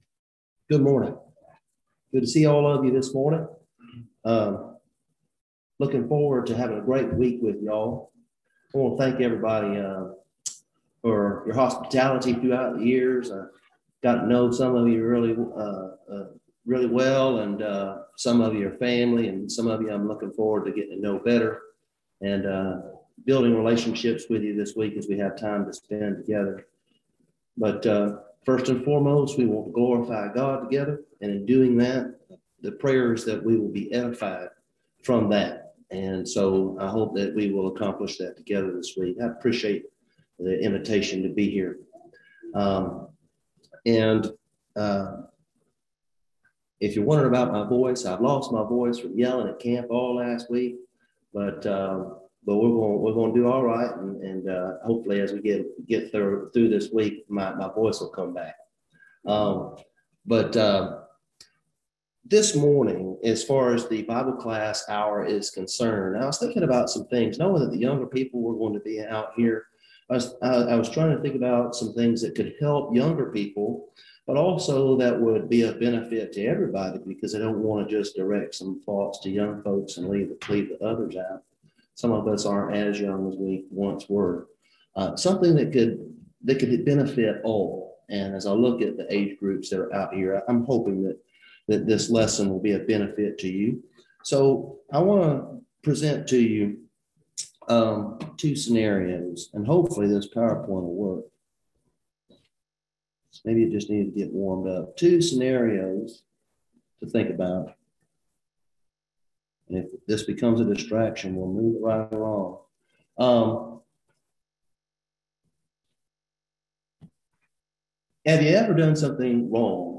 Service Type: Gospel Meeting Topics: Encouragement , forgiveness , Jesus Christ , Overcoming , Sin « What Is It Worth To You?